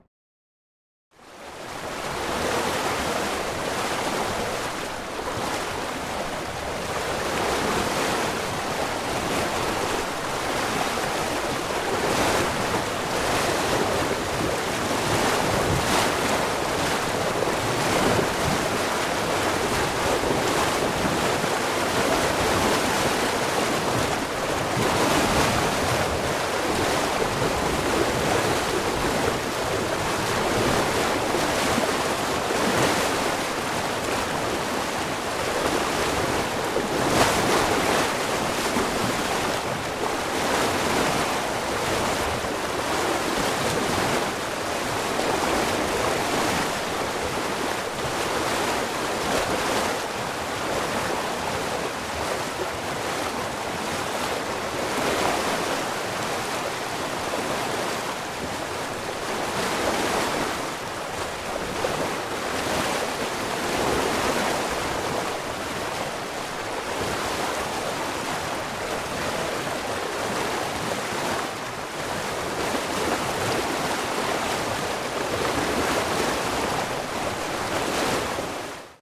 SailboatWithBowWash
Category: Sound FX   Right: Personal
Tags: Dungeons and Dragons Sea Ship